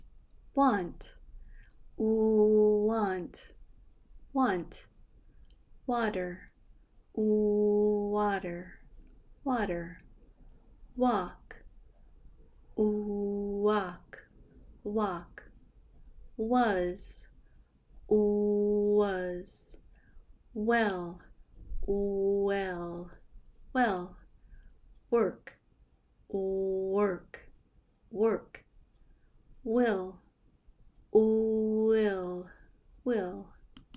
Pronounce W in American English
Now practice these words with the w sound from the John chapter 5: